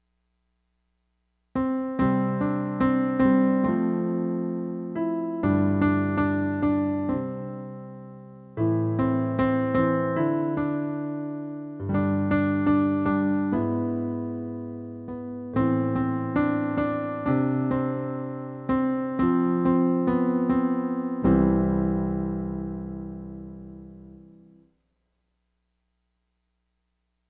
TransfigureUsOLord_Alto.mp3